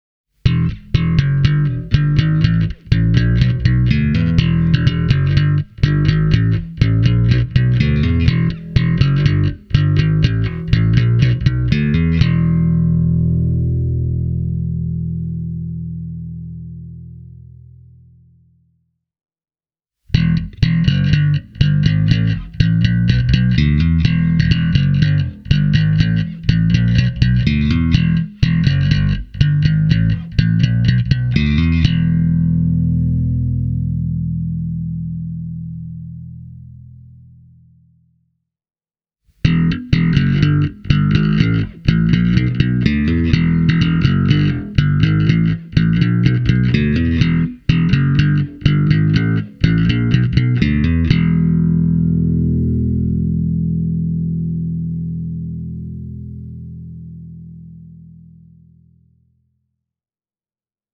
And this bass’ sound is outstanding, too, dishing out oodles of classic, Jazz Bass-style tones.
This means that using both pickups together gets rid of any possible electromagnetic hum and buzz (from transformers, lighting or displays).
Each clip has the neck pickup first, followed by both pickups on, and the bridge pickup going last:
amfisound-raudus-bass-e28093-slap.mp3